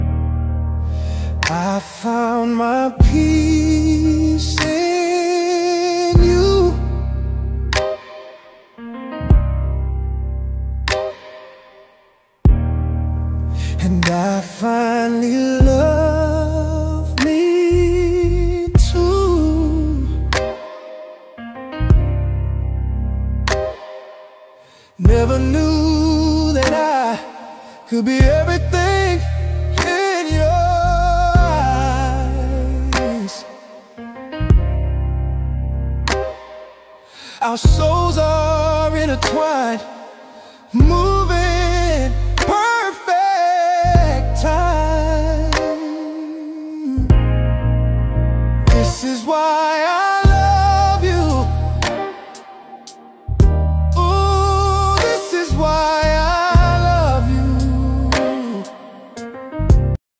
a lively, dance-worthy track
With its irresistible beat and dance-floor-ready appeal